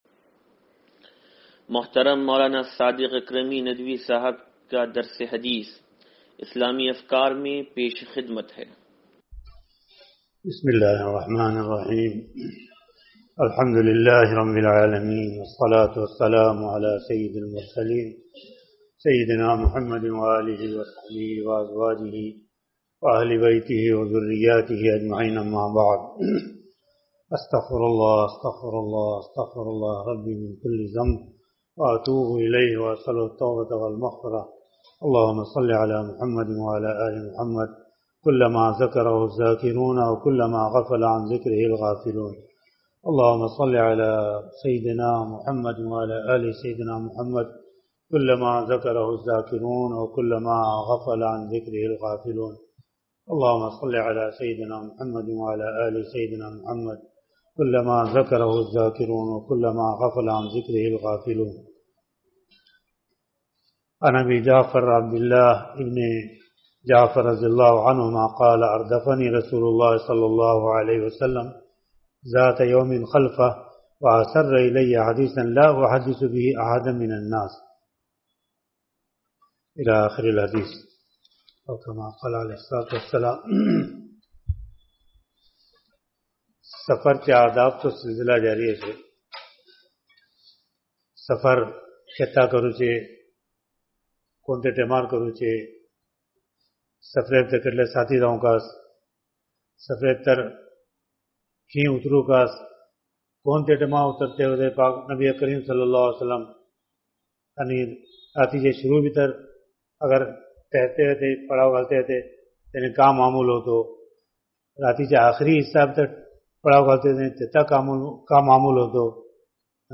درس حدیث نمبر 0756